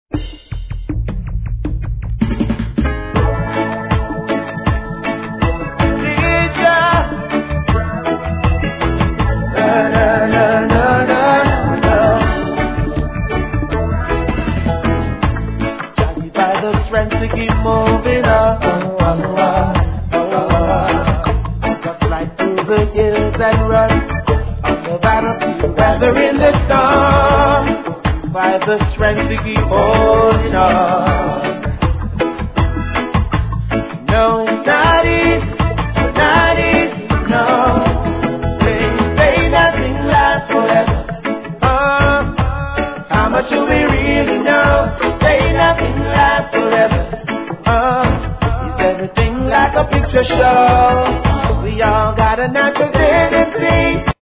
REGGAE
GROOVYなミディアムRHYTHMでソウルフルなヴォーカル!